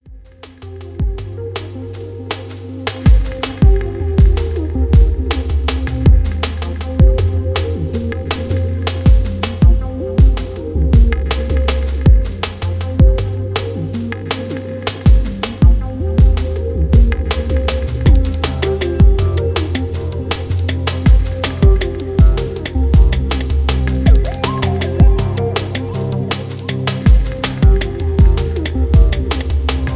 Tags: dnb, cinematic